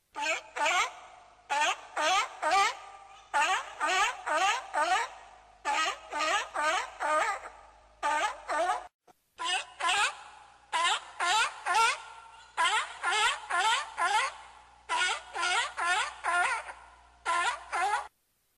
Ringetone Brule (lyd fra sælsæl)
Kategori Dyr
brule-lyd-fra-saelsael.mp3